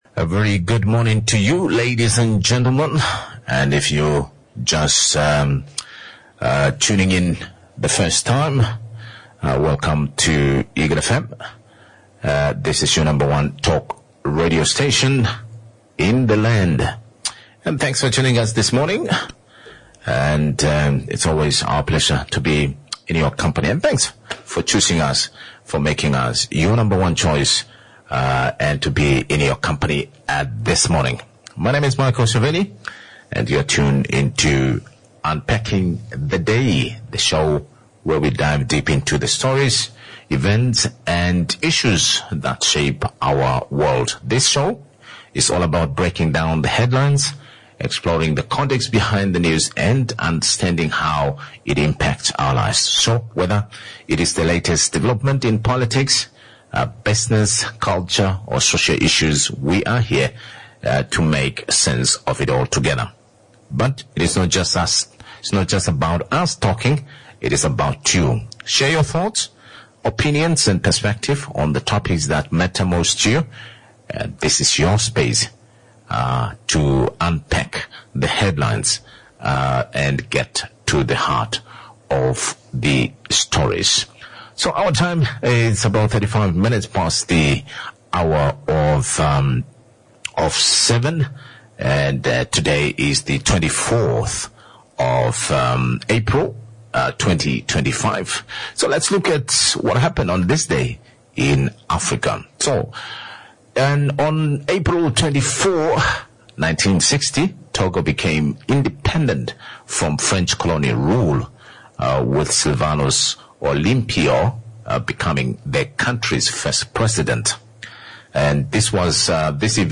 1. The SONA Special, interviewed